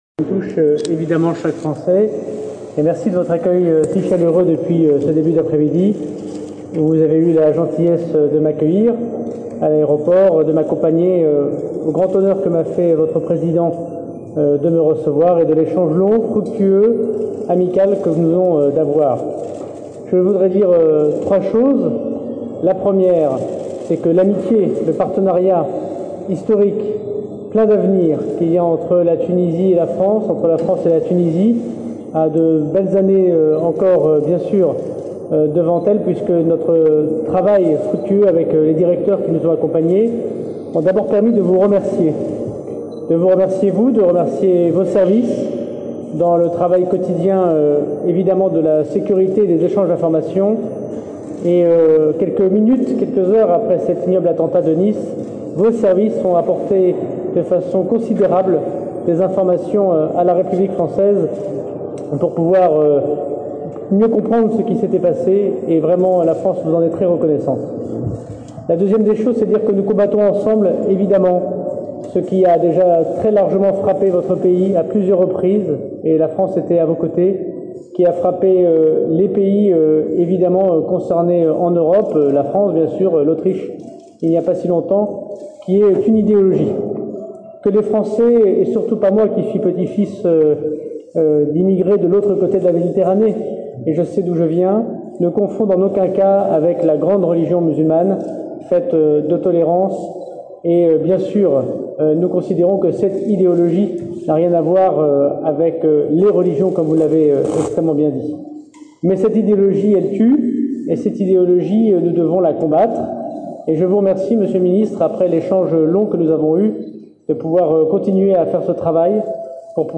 وأكد وزير داخلية فرنسا، خلال ندوة صحفية جمعته بعد ظهر اليوم بنظيره التونسي، أن الإيديولوجيا التي يتبعها منفذو العمليات الإرهابية سواء في تونس أو في الدول الأوروبية لا تمت بصلة للديانة الإسلامية التي تدعو إلى التسامح، واصفا الأفكار التي يؤمن بها الإرهابيون بـ"القاتلة"، والتي يجب مناهضتها.